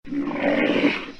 Badak_Suara.ogg